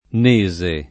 [ n %S e o n %@ e ]